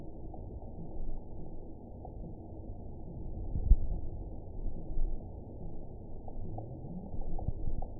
event 921885 date 12/20/24 time 09:37:15 GMT (4 months, 2 weeks ago) score 8.80 location TSS-AB03 detected by nrw target species NRW annotations +NRW Spectrogram: Frequency (kHz) vs. Time (s) audio not available .wav